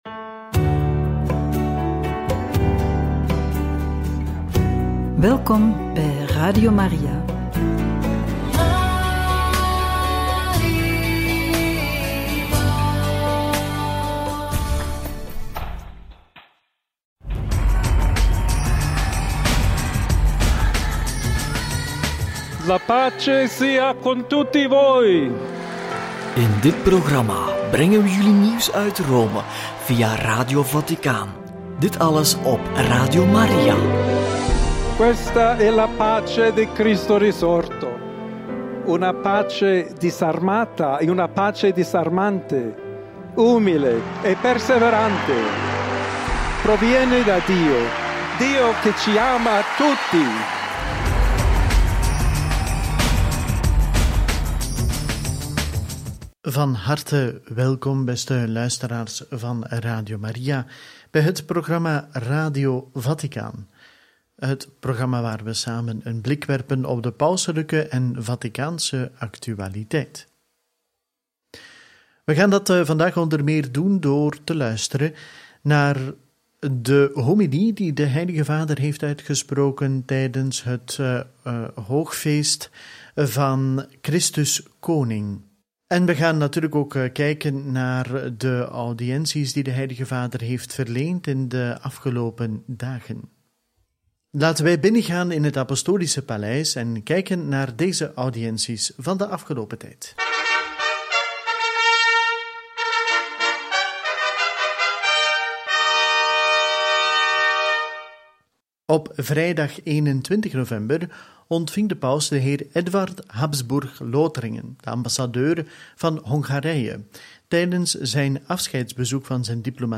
Paus spreekt Rota Romana toe en stelt verdediging van de huwelijksband centraal – Homilie Christus Koning – Radio Maria